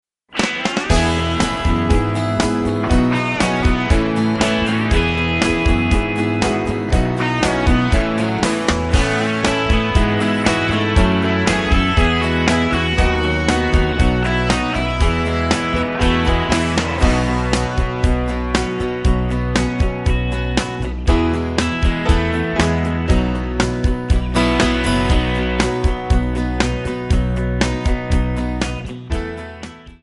Db
MPEG 1 Layer 3 (Stereo)
Backing track Karaoke
Country, 1990s